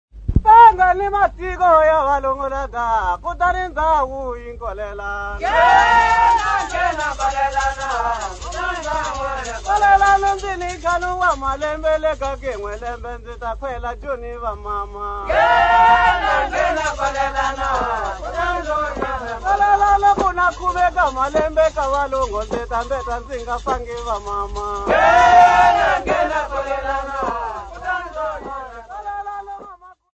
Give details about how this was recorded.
Field recordings Africa Mozambique city not specified f-mz